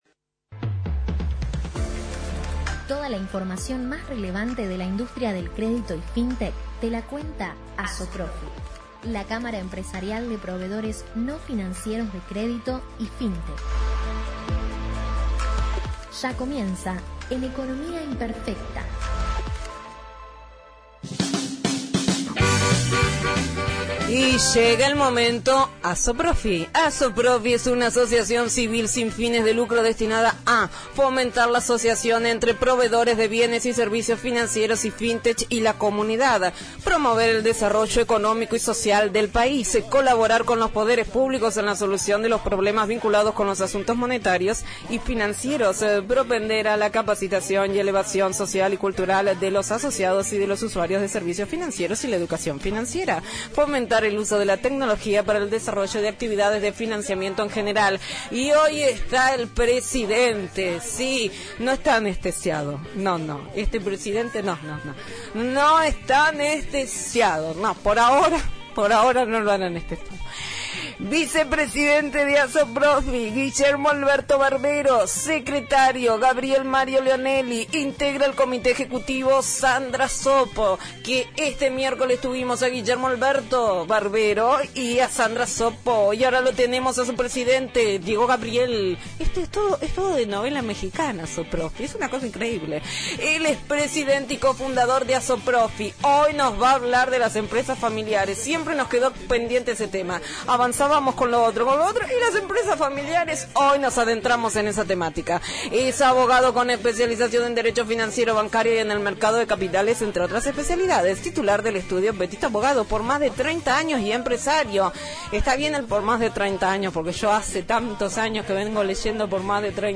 ASOPROFI – COLUMNA RADIAL – RADIO AM 1420 Viernes 19/11/21 ” Empresas Familiares”